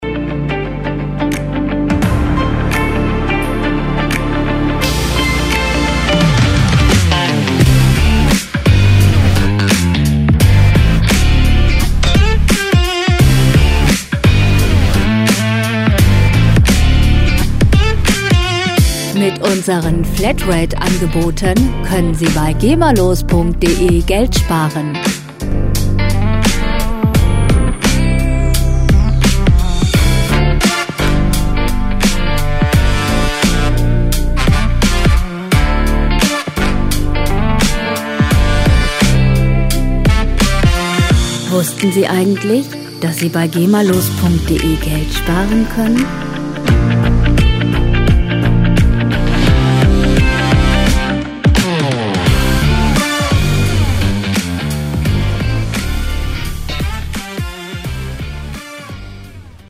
Werbemusik - Dynamik und Bewegung
Musikstil: Urban Funk
Tempo: 86 bpm
Tonart: D-Moll
Charakter: selbstbewusst, breitbeinig